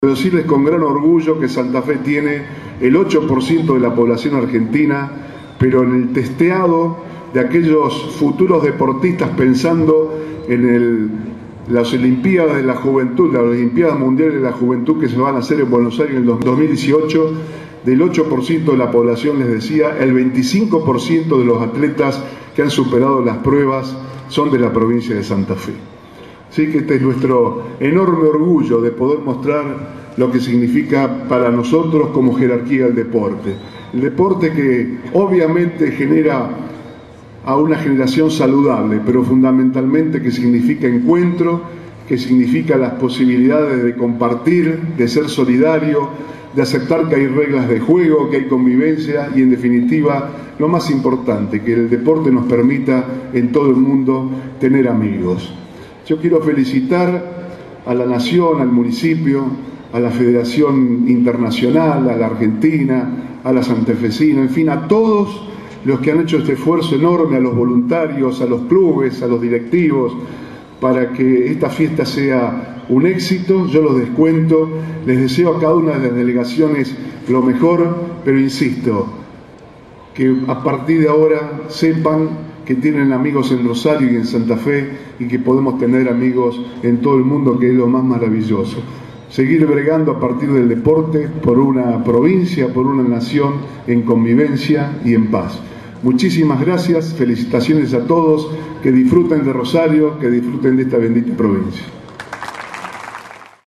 El gobernador Antonio Bonfatti y la intendenta de Rosario, Mónica Fein, encabezaron en el Monumento Nacional a la Bandera, la apertura oficial del Mundial de Patinaje de Velocidad que se desarrollará hasta el 15 de noviembre en la ciudad, con la presencia de más de 400 competidores de 33 países.
En sus palabras, el gobernador dio a los presentes la “bienvenida a la provincia de Santa Fe y a Rosario, Cuna de la Bandera, que nos distingue entre todos los países del mundo”.